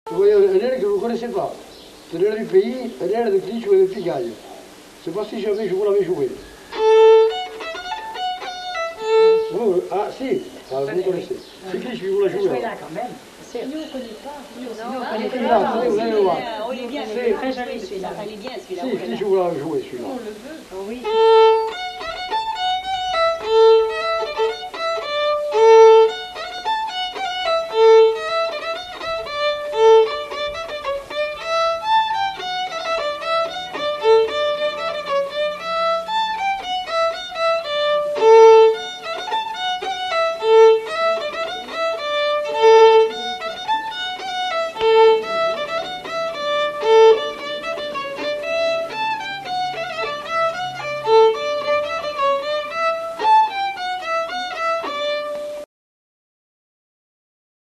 Aire culturelle : Couserans
Département : Ariège
Genre : morceau instrumental
Instrument de musique : violon
Danse : polka piquée